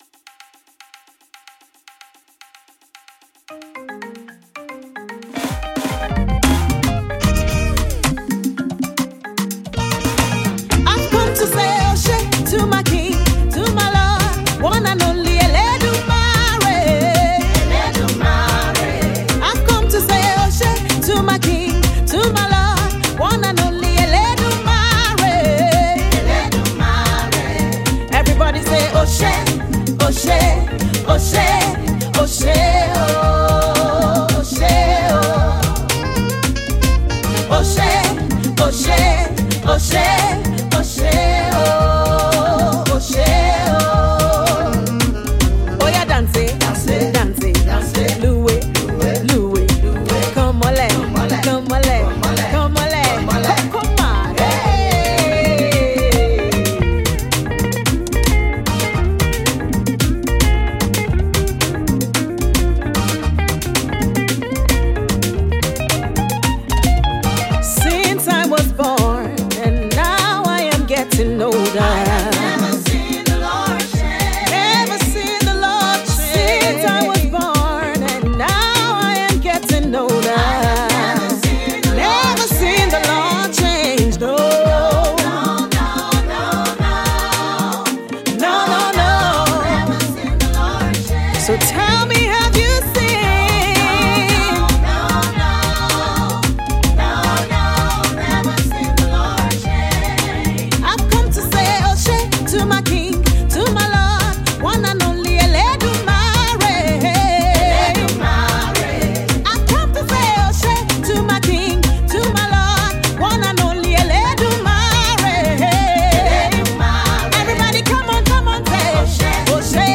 folk song of praise and adoration